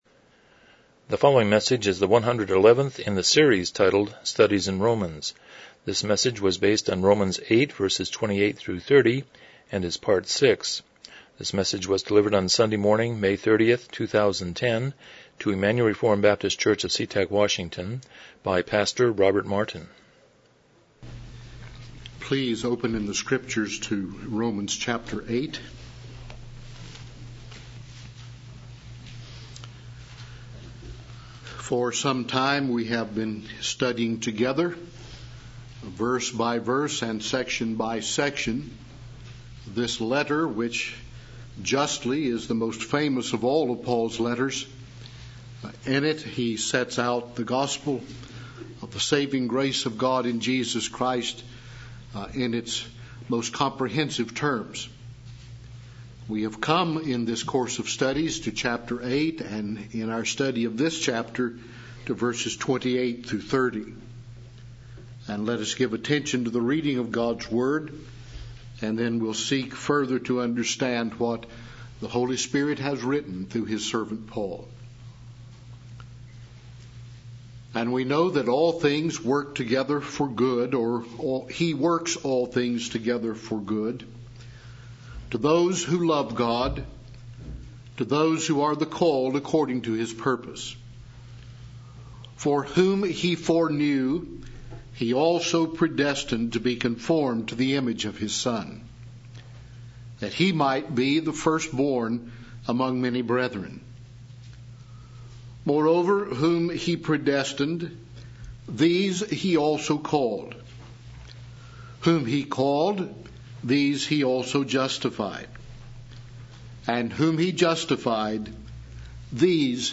Romans 8:28-30 Service Type: Morning Worship « 97 Chapter 18.4